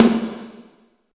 50 VRB SN2-R.wav